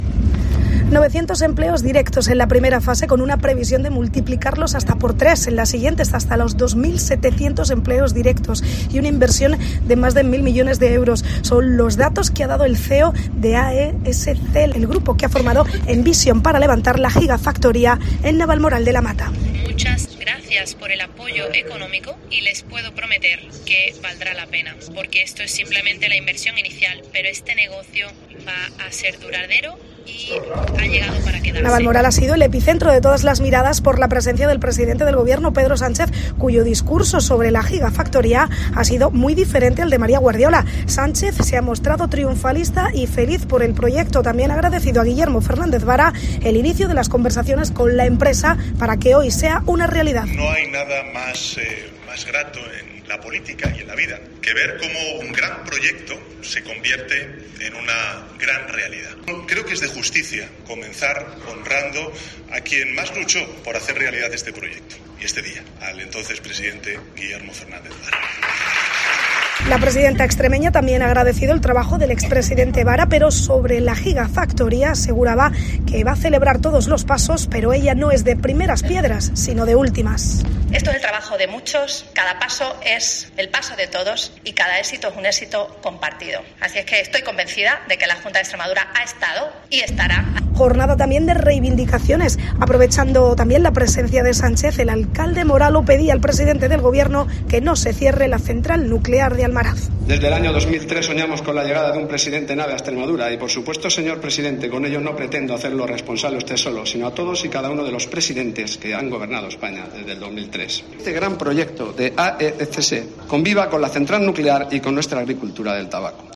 La llegada de Pedro Sánchez movilizó en Navalmoral a cientos de trabajadores y familiares de la Central Nuclear de Almaraz, quienes pedían a gritos en la entrada del acto del presidente que: “¡Almaraz no se cierre!”.